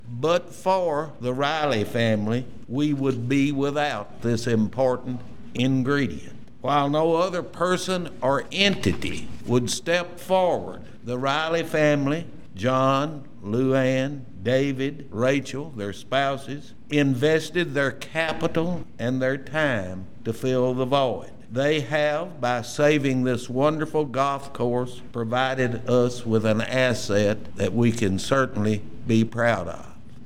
spoke to those in attendance on the importance of having a golf and country club in a community